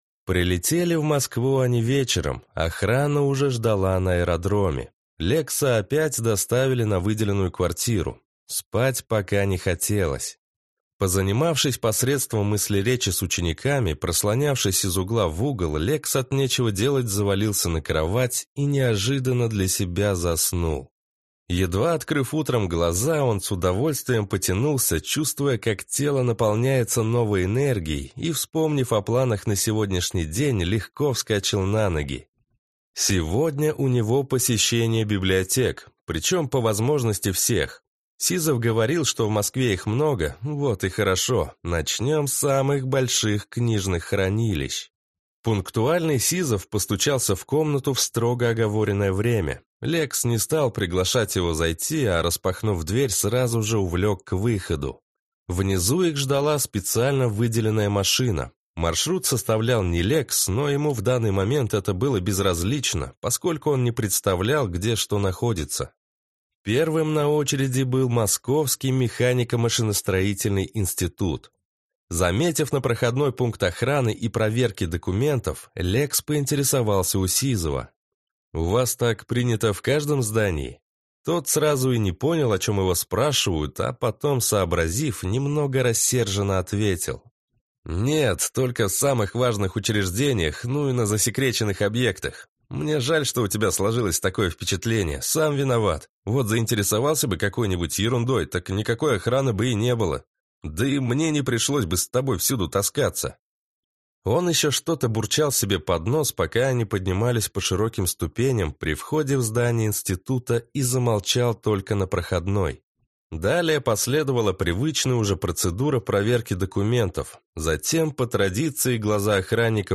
Аудиокнига Артефактор+. Книга 1. Шаг в неизвестность. Том 2 | Библиотека аудиокниг